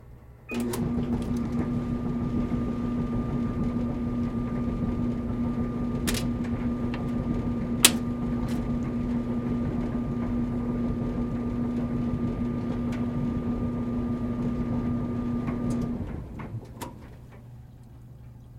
衣物烘干机
描述：干衣机的开启和关闭
Tag: 翻滚 车削 哼唱 衣服 电机 干燥机 干燥